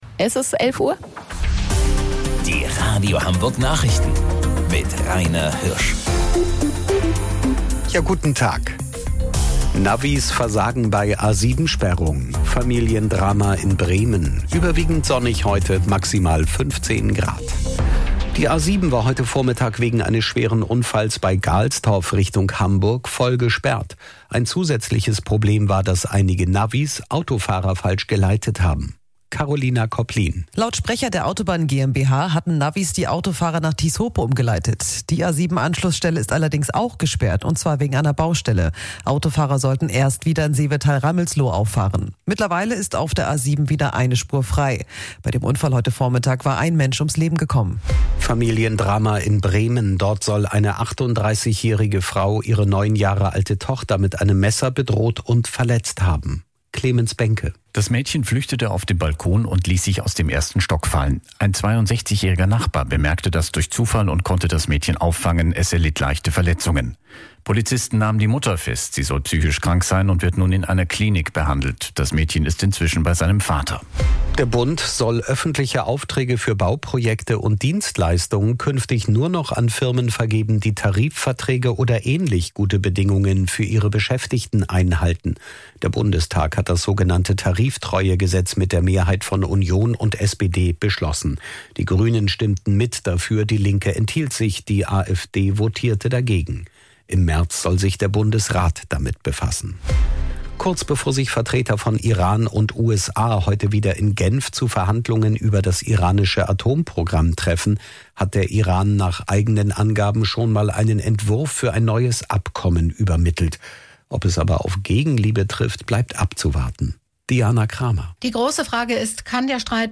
Radio Hamburg Nachrichten vom 26.02.2026 um 11 Uhr